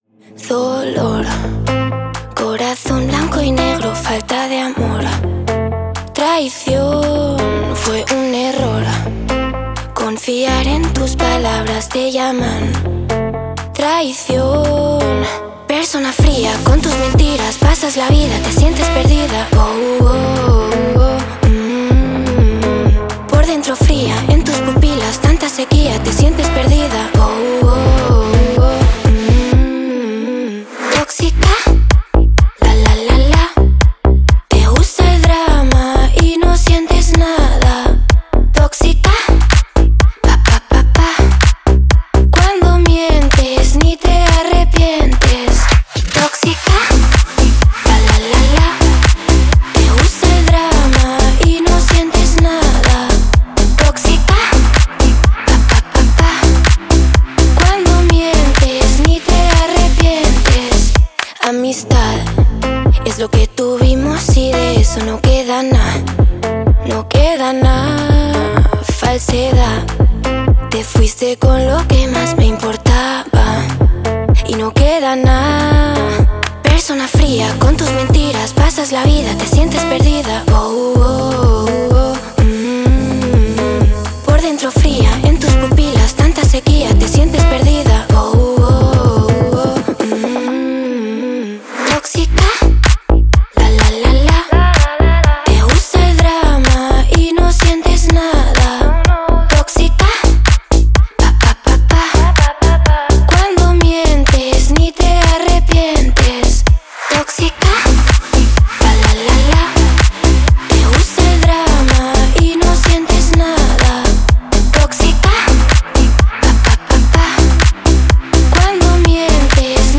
зажигательный трек